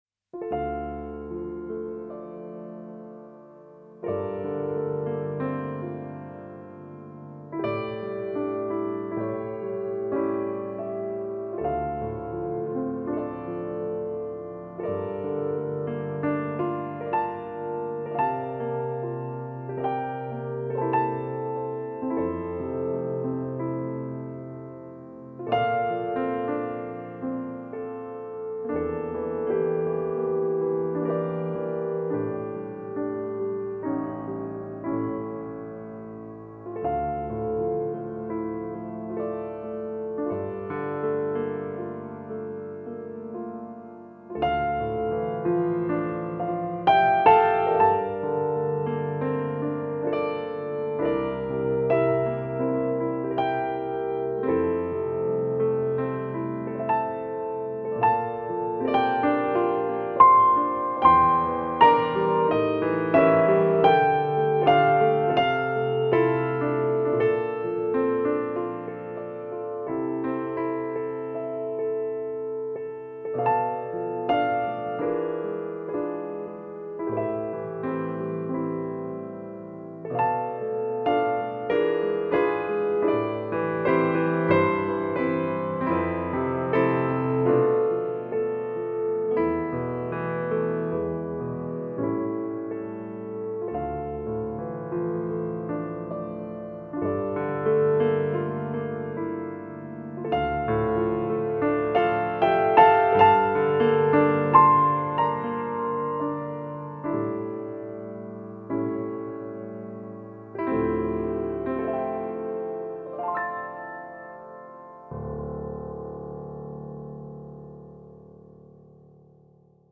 ．音乐类别：新世纪治疗系音乐
．演 奏 家：钢琴
笛.箫
温暖而流畅，如澄澈的流水般洗涤尘嚣，抚平了人们的浮躁不安，
钢琴独白加上后段弦乐的铺陈，